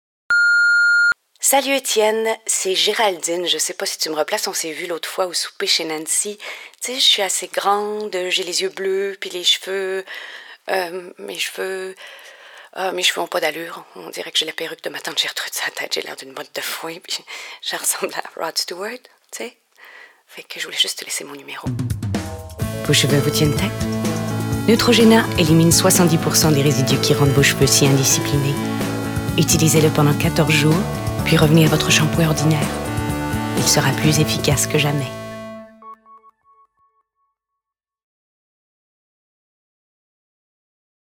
DÉMO VOIX
Comédienne, auteur, metteur en scène